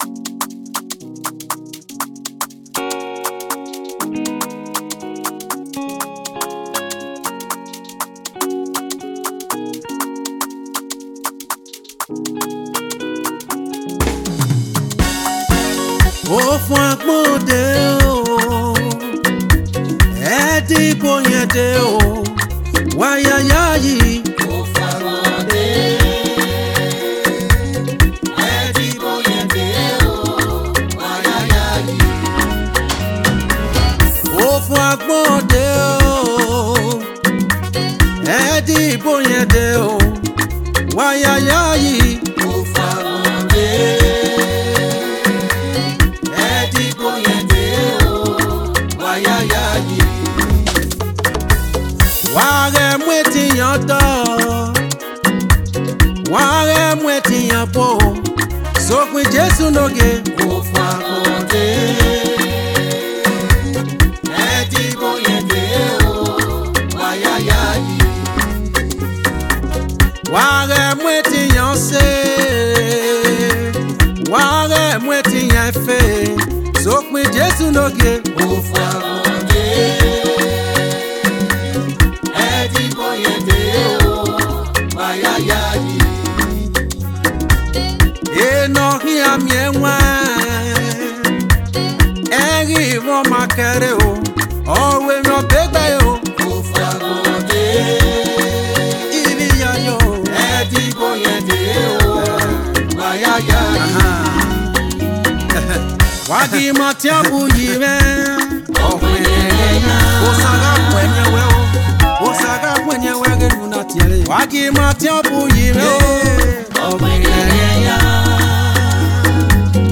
Edo song